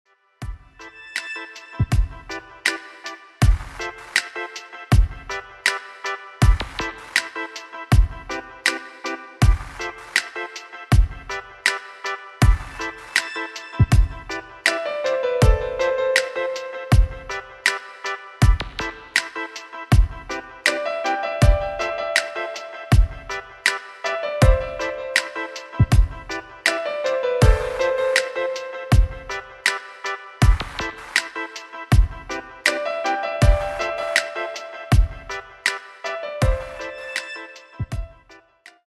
• Качество: 192, Stereo
спокойные